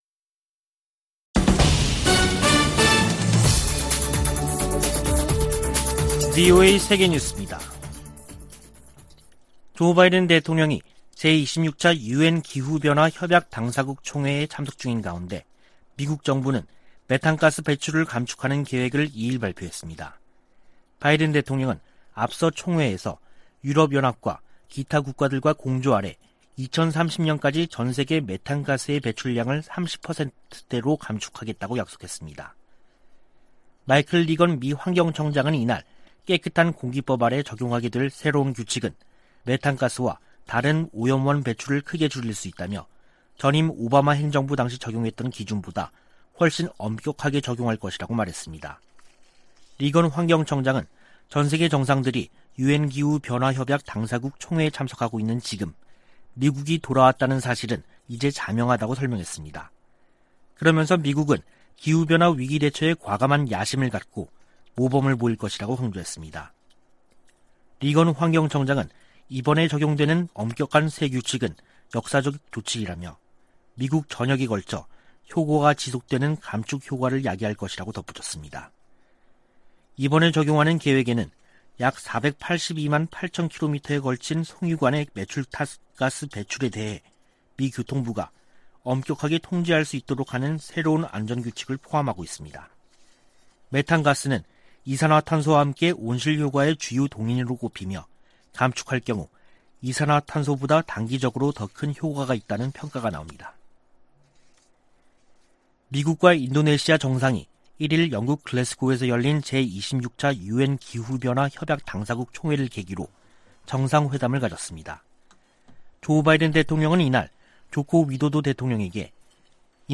VOA 한국어 간판 뉴스 프로그램 '뉴스 투데이', 2021년 11월 2일 3부 방송입니다. 중국과 러시아가 유엔 안전보장이사회에 대북제재 완화를 위한 결의안 초안을 다시 제출했습니다.